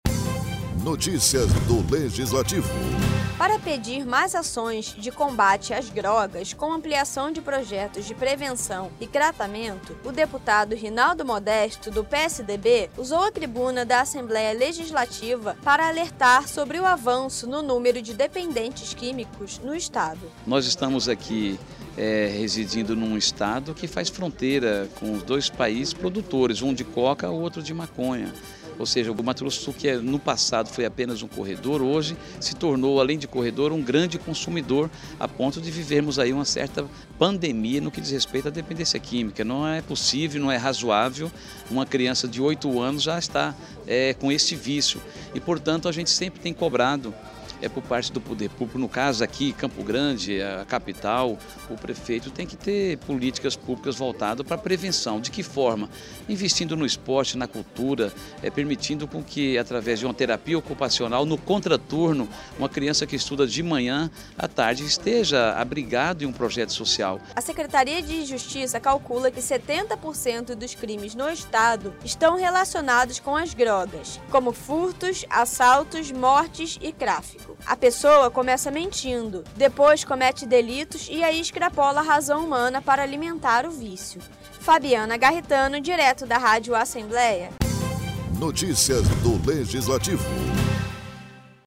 O deputado estadual Rinaldo Modesto, do PSDB usou à tribuna da Assembleia Legislativa para pedir mais ações de combate às drogas, com ampliação de projetos de prevenção e tratamento.